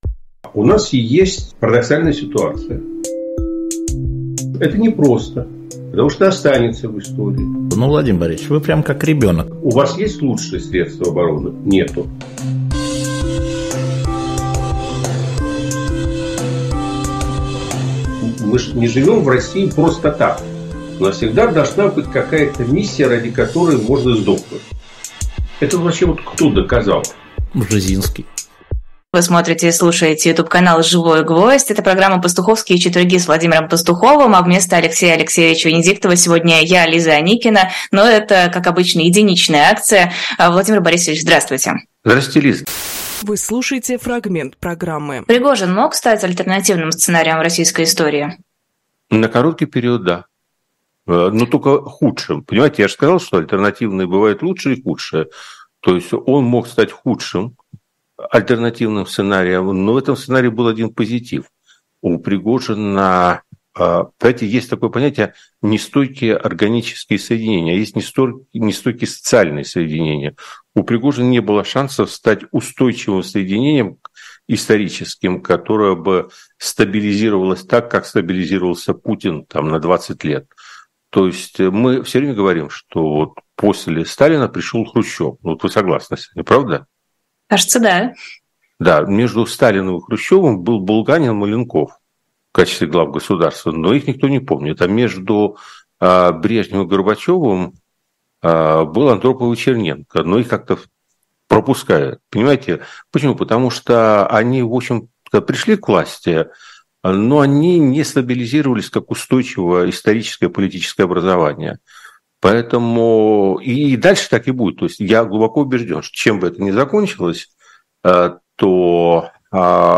Фрагмент эфира от 31.08.23